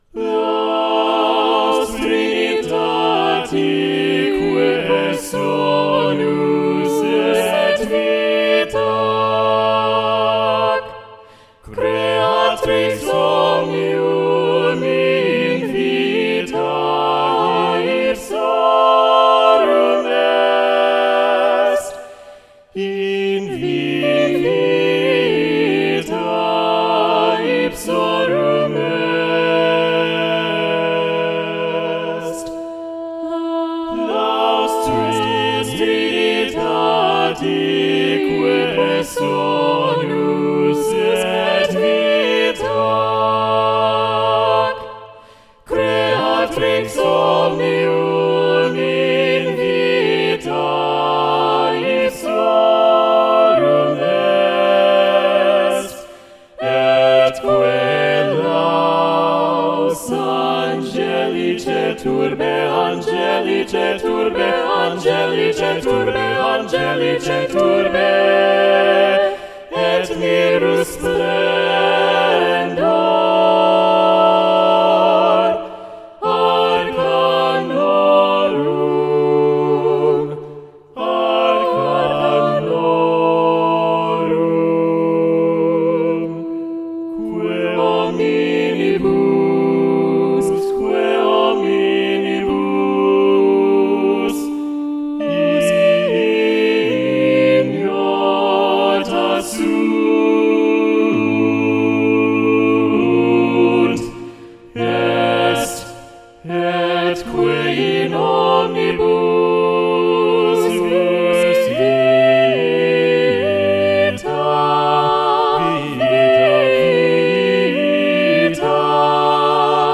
Voicing: SATB
Instrumentation: a cappella